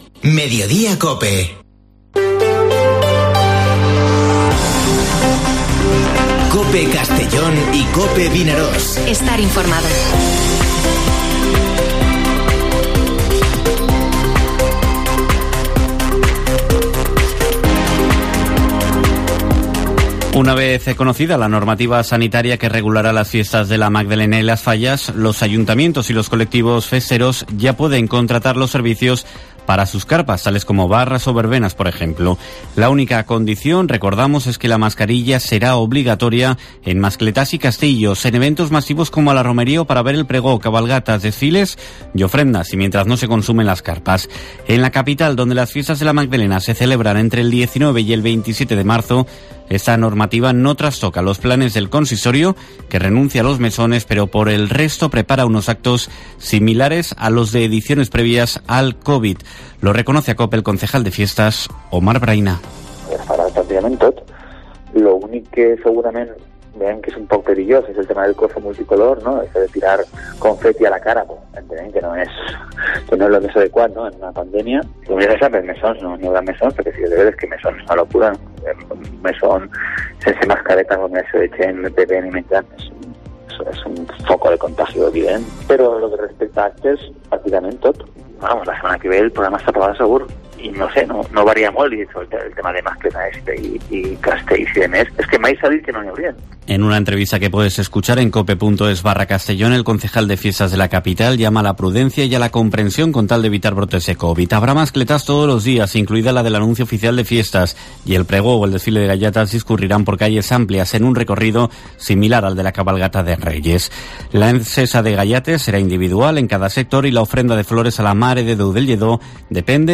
Informativo Mediodía COPE en la provincia de Castellón (15/02/2022)